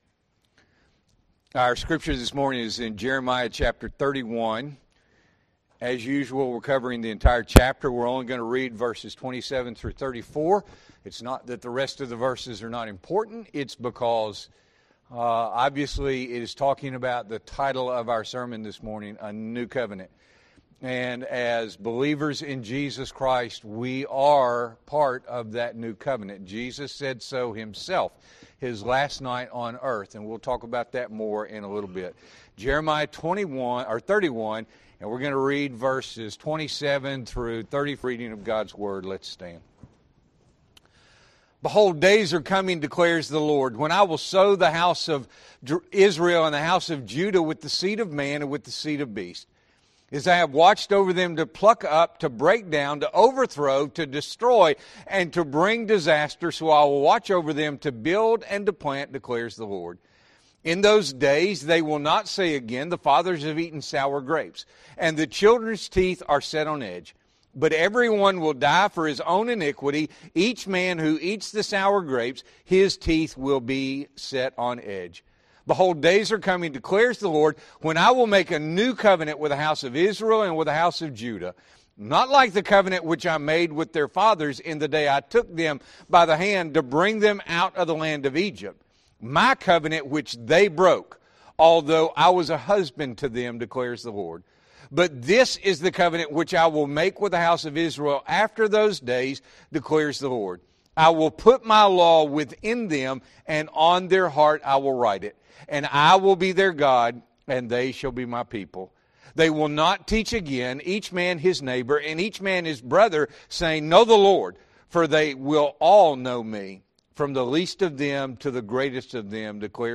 April 27, 2025 – Morning Worship